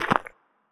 step-2.wav